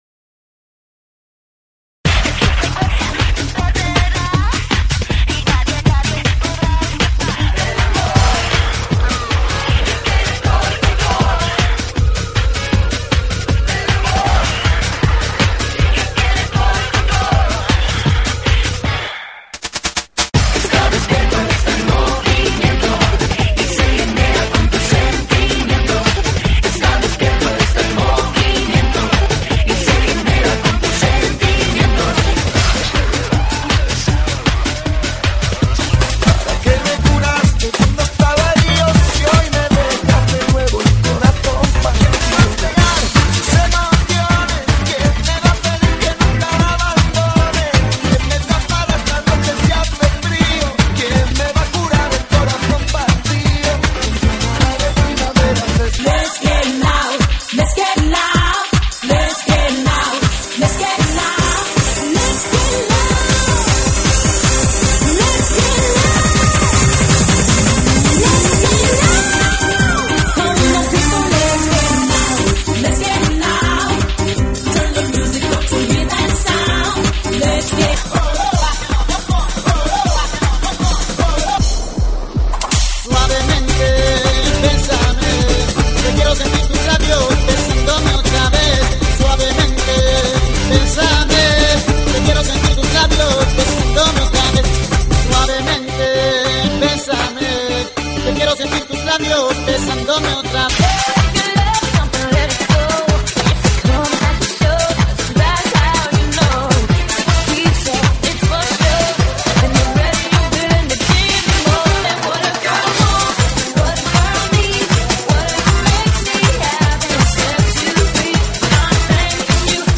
GENERO: RADIO – RETRO